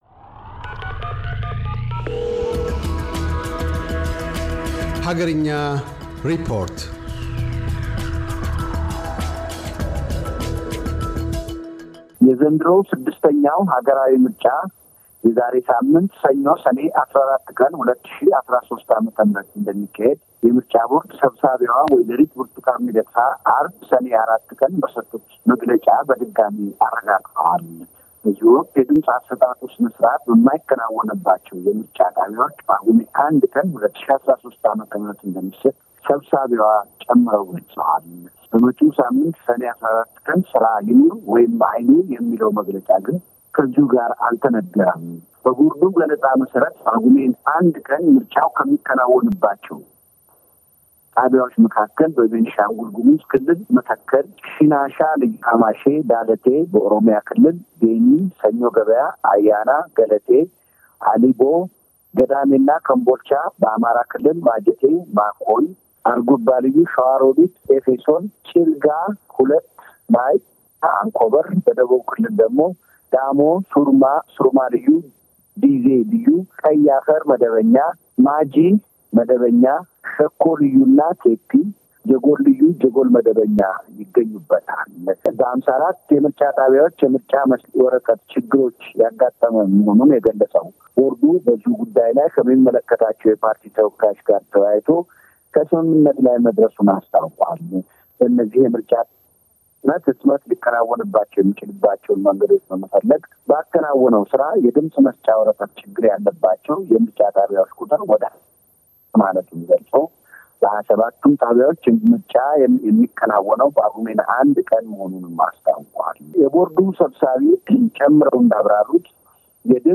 *** አገርኛ ሪፖርት